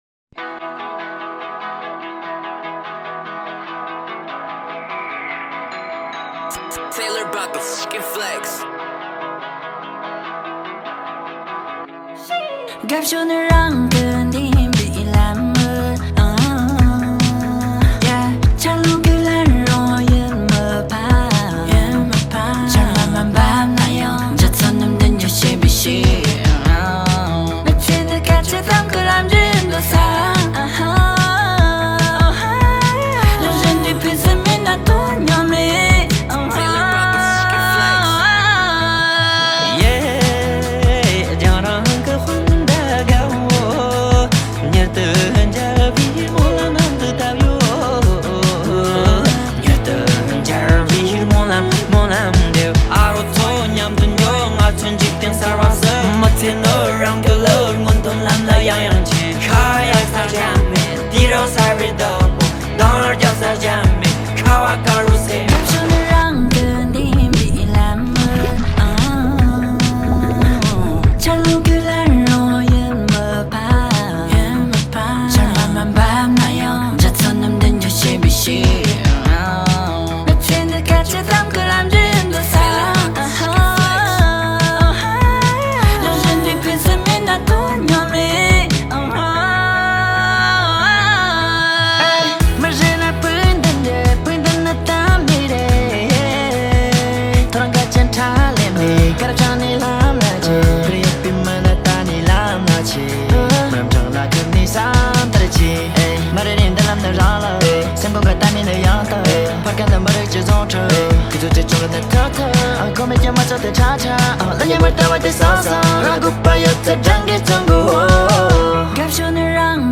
数名西藏境内歌手联合演唱的歌曲